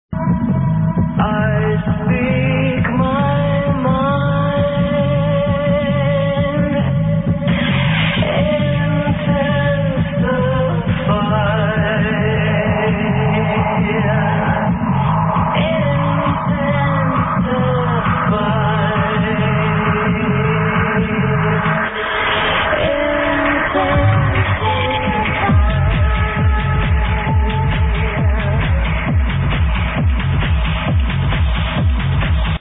unknown progressive song